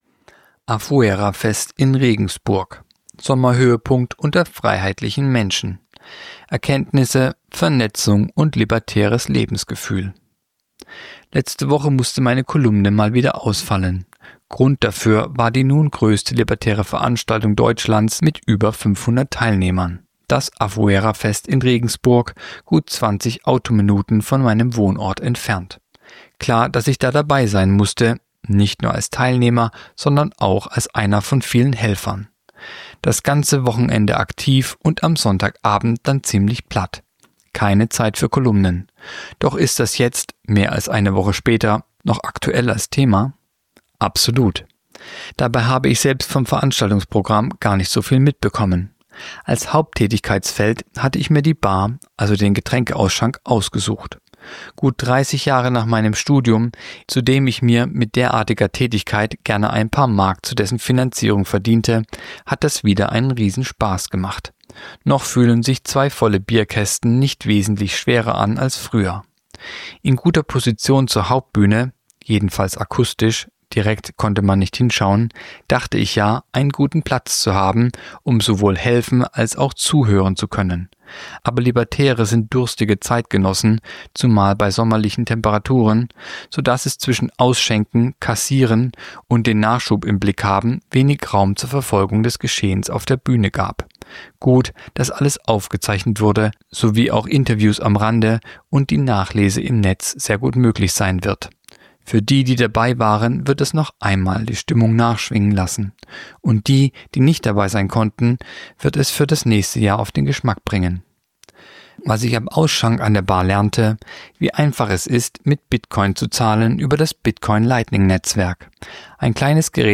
Kolumne der Woche (Radio)Sommerhöhepunkt unter freiheitlichen Menschen